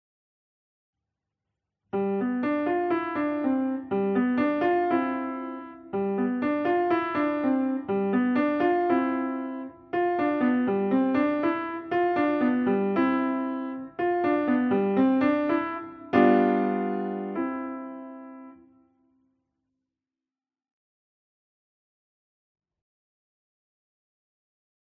Besetzung: Klavier